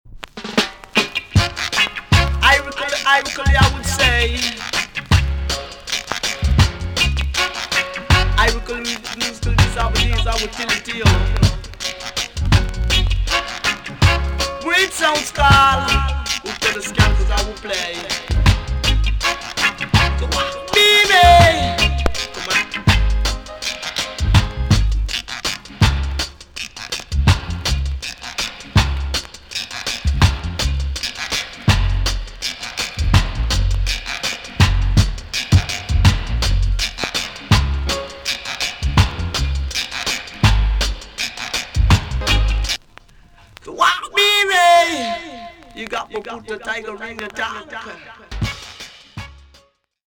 TOP >REGGAE & ROOTS
VG+~VG ok 一箇所キズがありプチノイズが入ります。